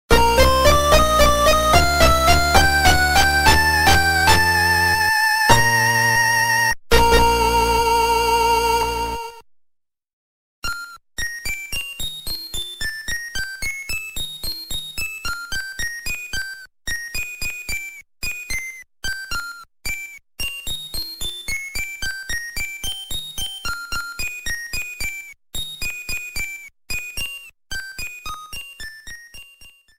Ending theme